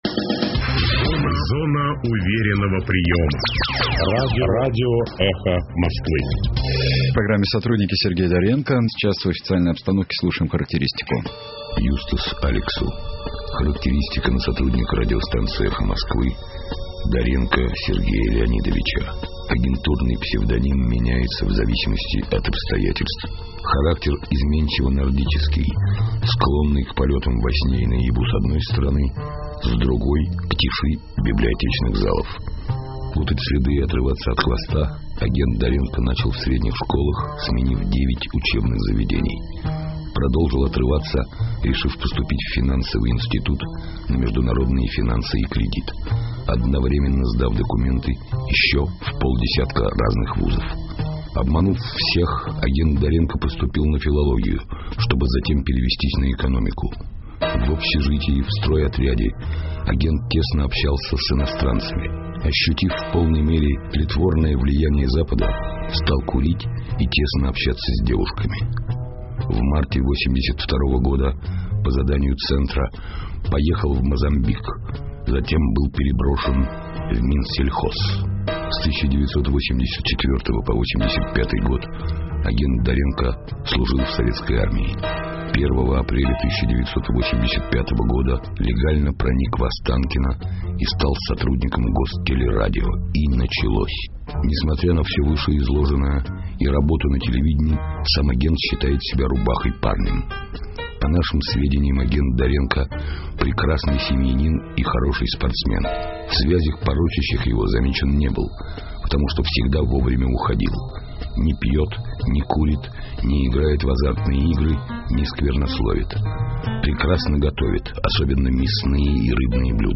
В прямом эфире радиостанции "Эхо Москвы" программа "Сотрудники"